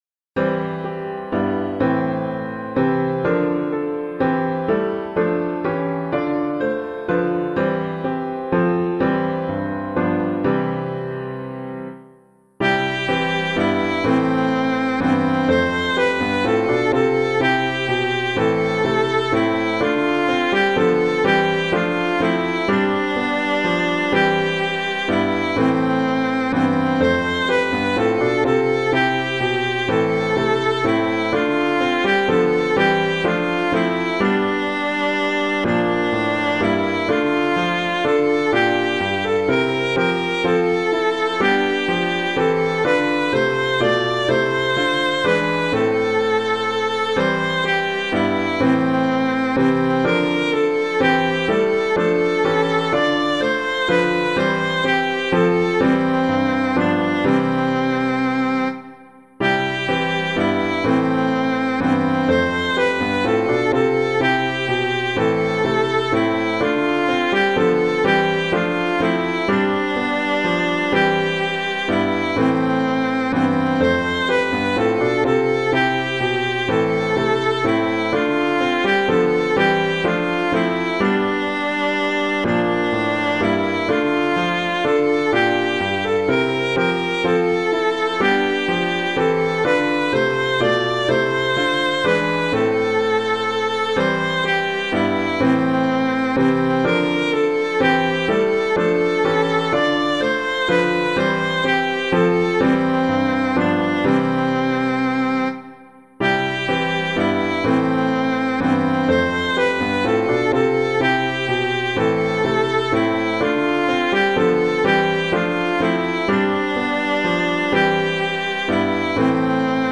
Hymn of the Day:  5th Sunday of Lent, Year C
piano